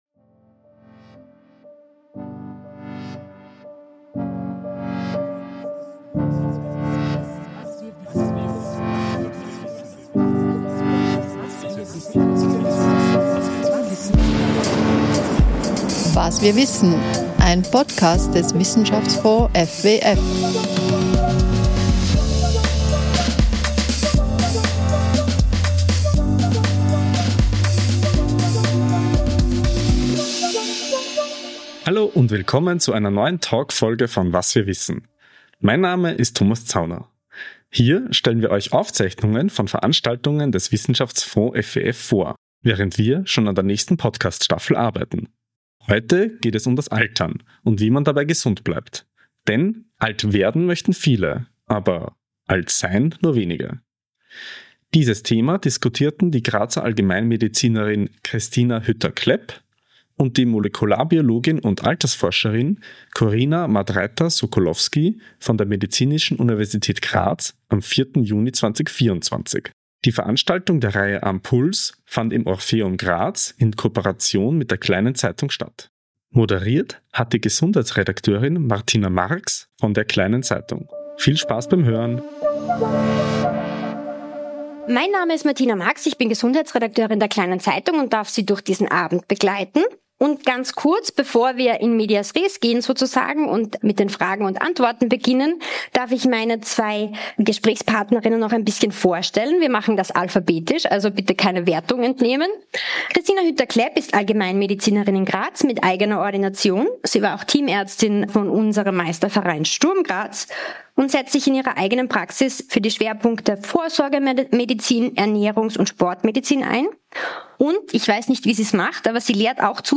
Diese Veranstaltung fand im Orpheum in Graz in Kooperation mit der Kleinen Zeitung statt.